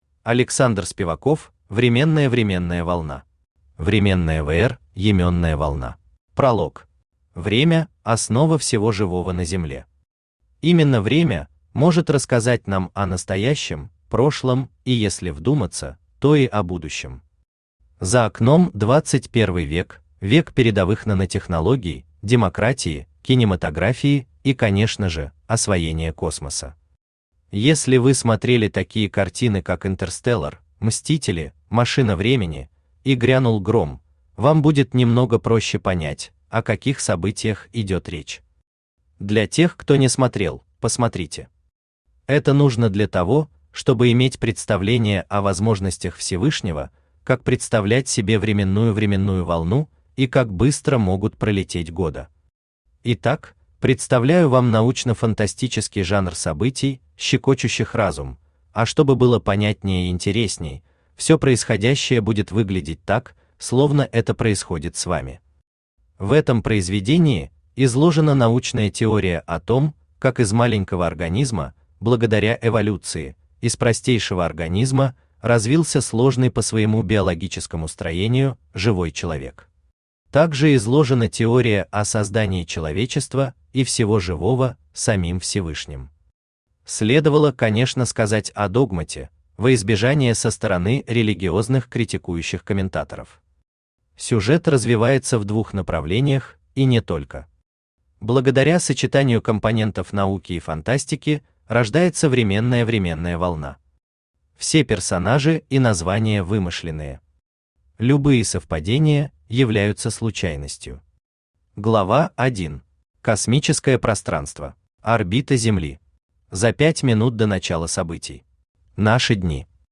Аудиокнига Временная временная волна | Библиотека аудиокниг
Aудиокнига Временная временная волна Автор Александр Юрьевич Спиваков Читает аудиокнигу Авточтец ЛитРес.